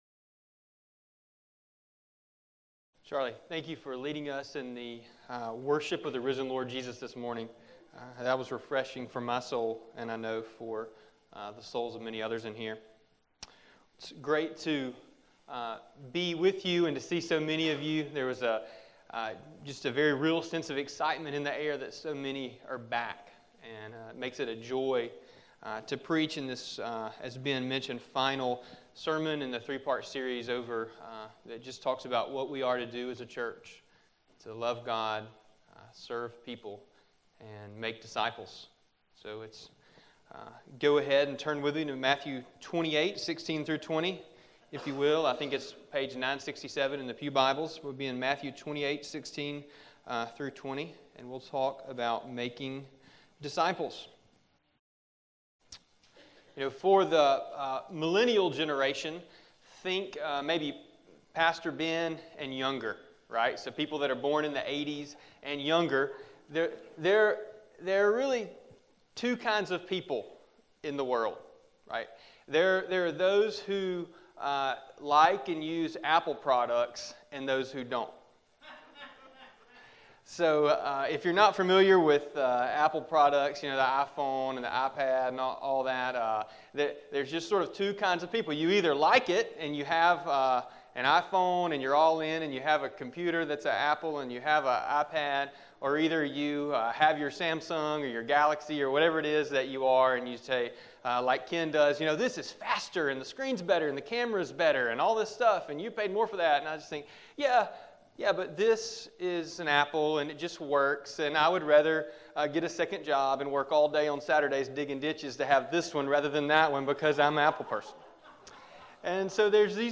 This is the third and final message in the Core Values sermon series.